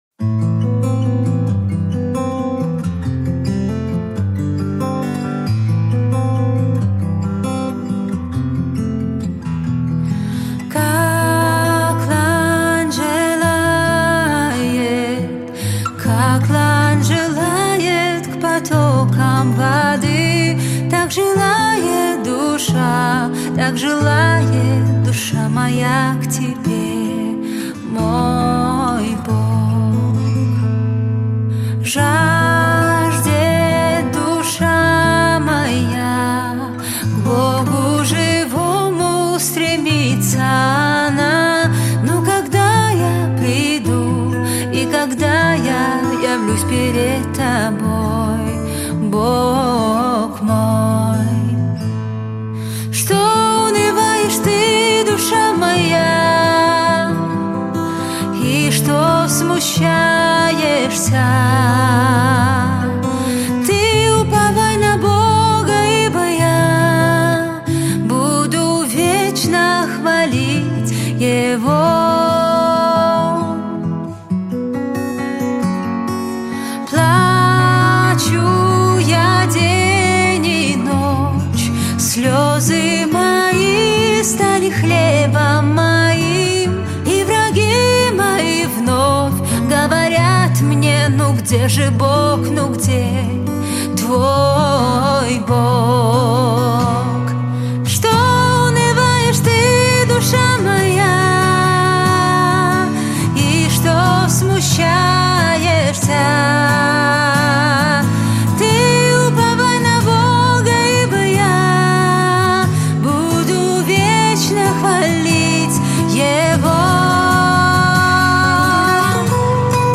(Acoustic)